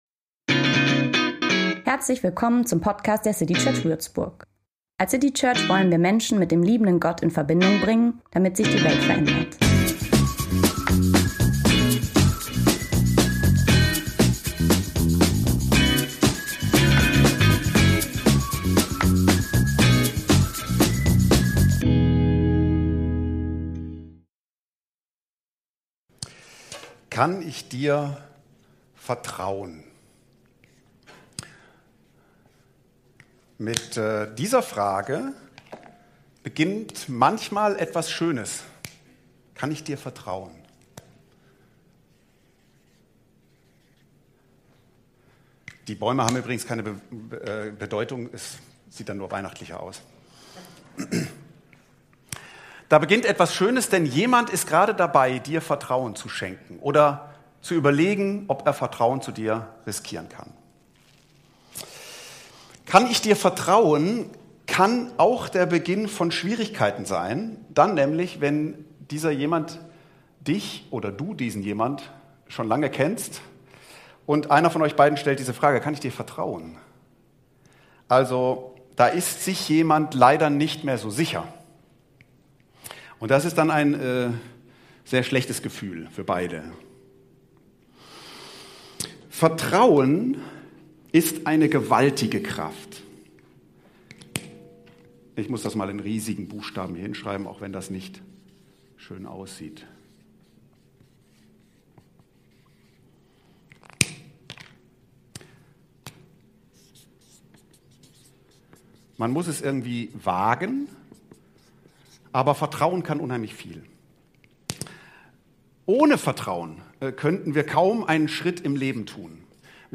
Weihnachtsgottesdienst ~ CityChurch Würzburg Podcast